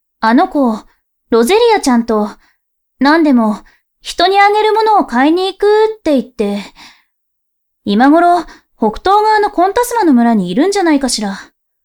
性別：女